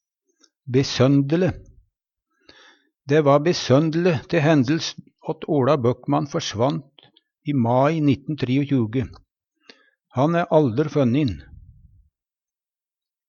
besønd'le - Numedalsmål (en-US)
besond-le.mp3